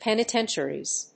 /ˌpɛnɪˈtɛntʃɝiz(米国英語), ˌpenɪˈtentʃɜ:i:z(英国英語)/